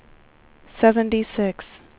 WindowsXP / enduser / speech / tts / prompts / voices / sw / pcm8k / number_96.wav
number_96.wav